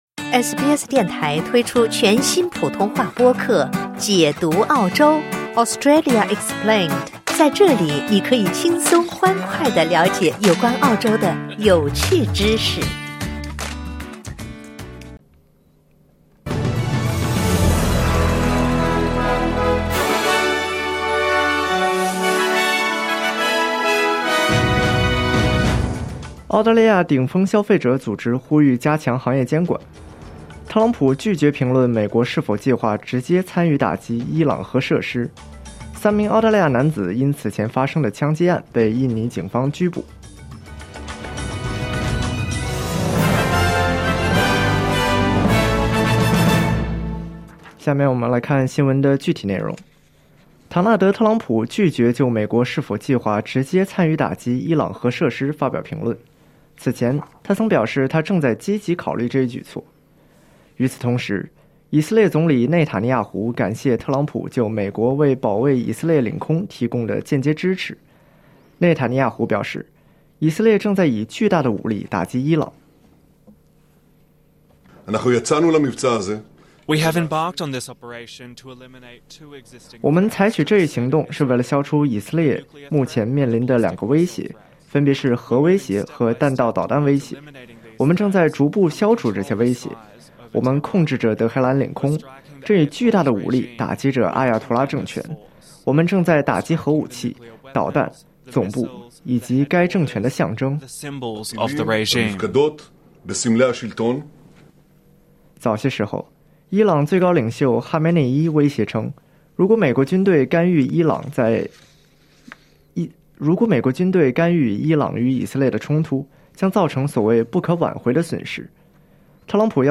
SBS早新闻（2025年6月19日）
SBS 新闻快报